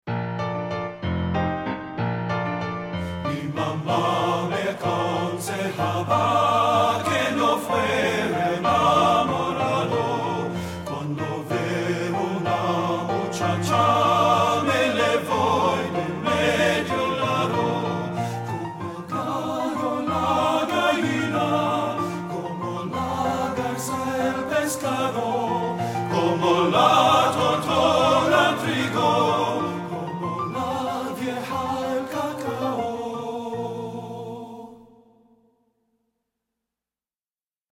Voicing: TB